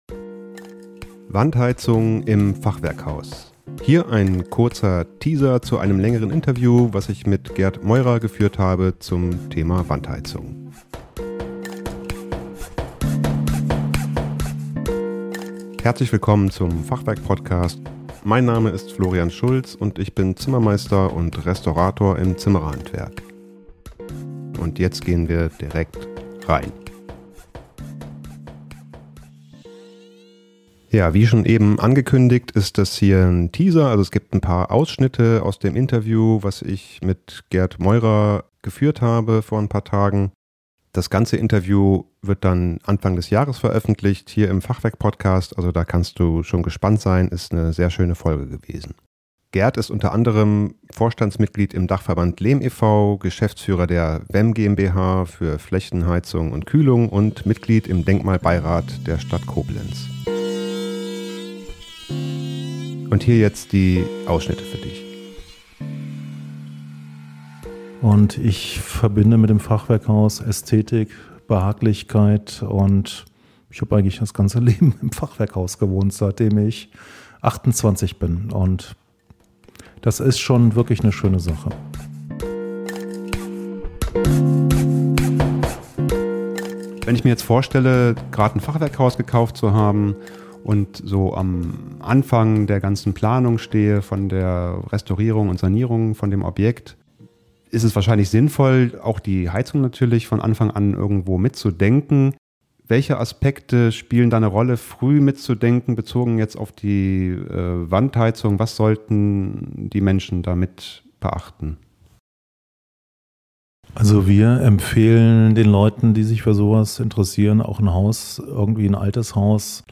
Wandheizung im Fachwerkhaus - Teaser zum Interview